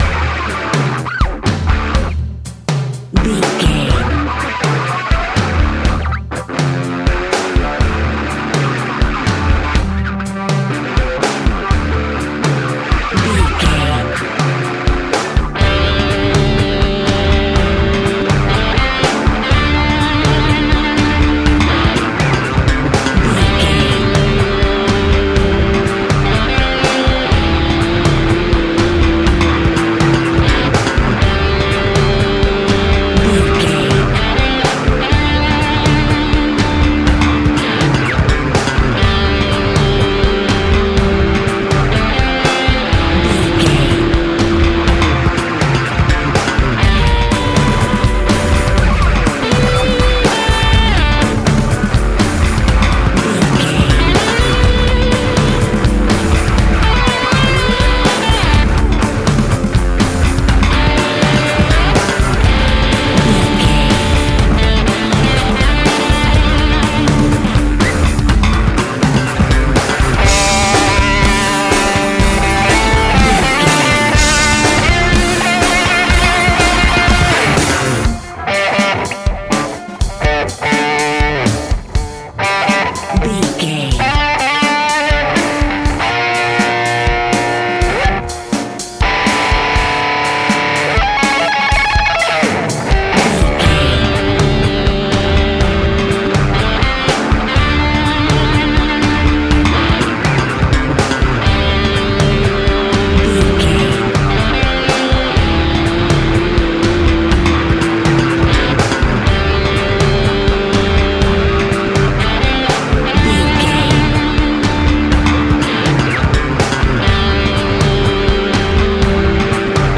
Ionian/Major
electric guitar